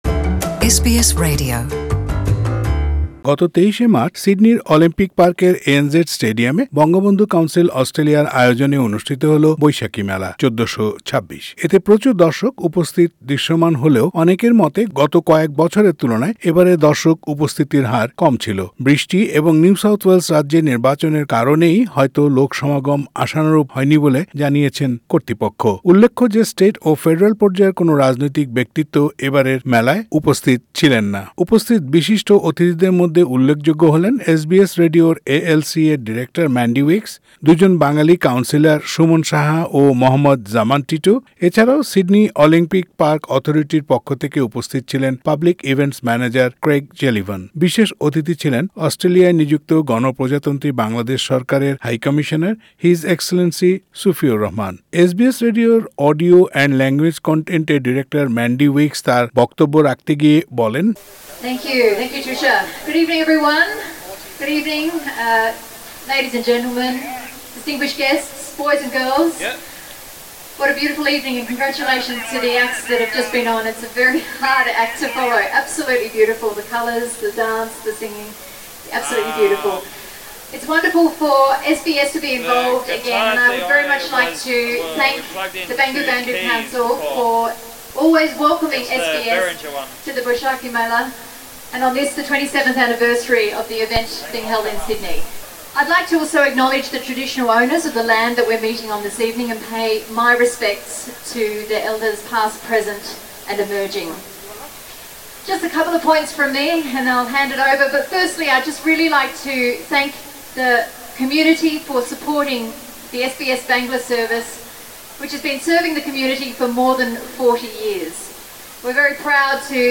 Source: SBS প্রতিবেদনটি বাংলায় শুনতে উপরের অডিও প্লেয়ারটিতে ক্লিক করুন।